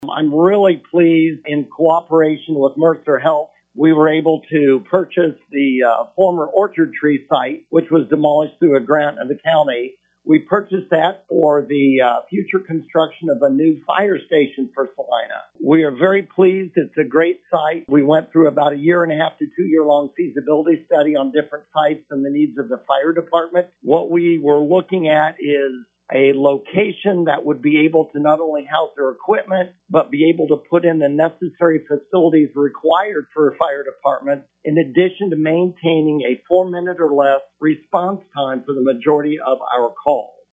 HERE IS MAYOR HAZEL…